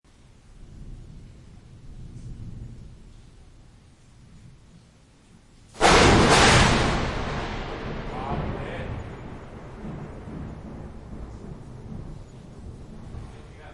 Scary Thunder And Lightning Sound Button - Free Download & Play